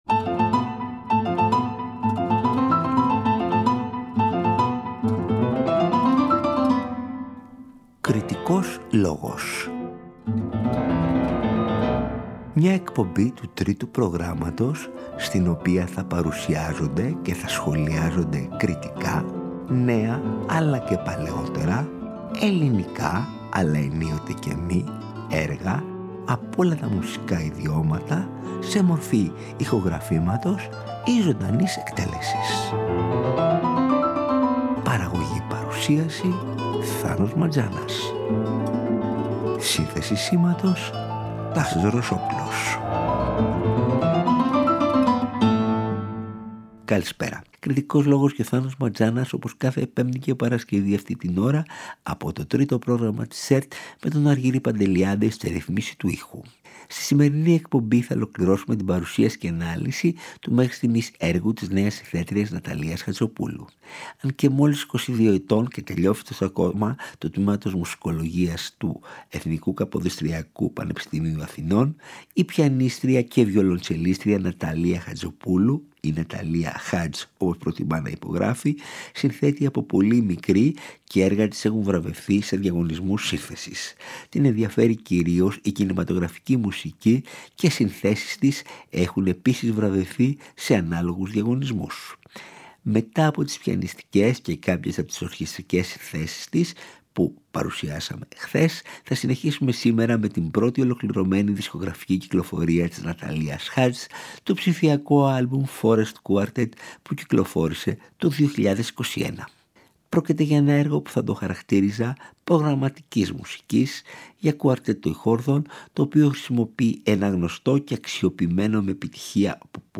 Πρόκειται για ένα έργο προγραμματικής μουσικής για κουαρτέτο εγχόρδων το οποίο χρησιμοποιεί ένα γνωστό και αξιοποιημένο με επιτυχία από πολλούς και πολλές δημιουργούς εύρημα, αυτό του soundtrack μιας φανταστικής ταινίας.